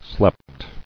[slept]